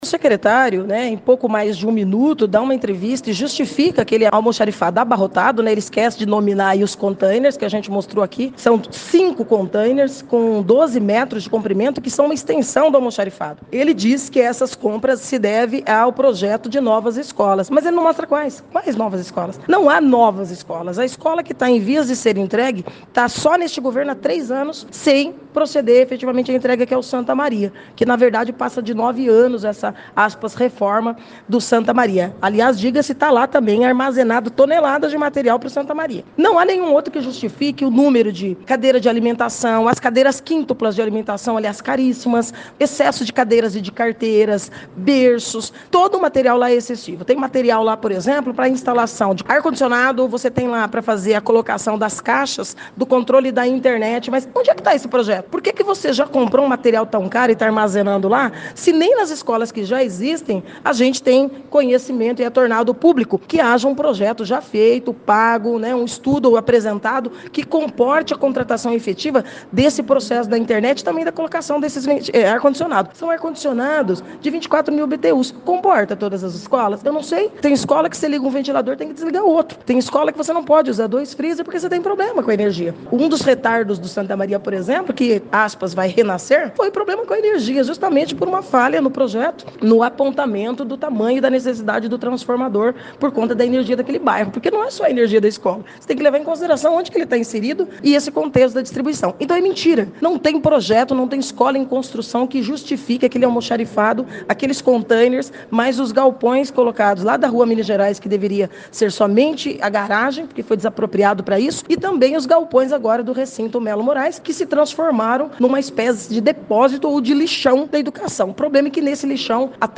Diligência realizada pela Comissão de Fiscalização ao almoxarifado central da secretaria da educação na última semana, ganhou destaque na sessão legislativa desta segunda-feira.
Presidente da Comissão, vereadora Estela Almagro, disse que o secretário Nilson Ghirardello, em entrevista à 94FM, esqueceu-se de dizer quais escolas serão entregues.